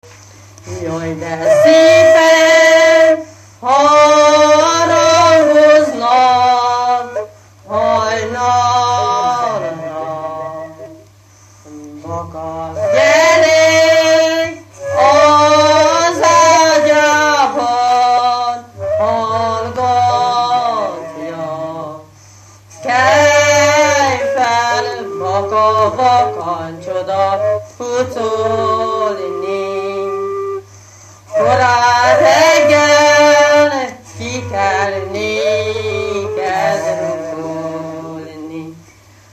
Dunántúl - Somogy vm. - Berzence
hosszúfurulya
ismeretlen (sz. ), ének
Műfaj: Keserves
Stílus: 6. Duda-kanász mulattató stílus